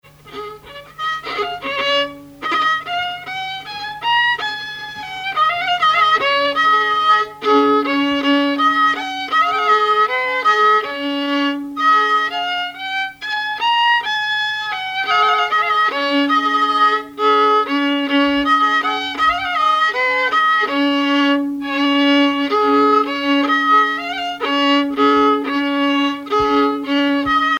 violoneux, violon
musique traditionnelle
circonstance : fiançaille, noce
Pièce musicale inédite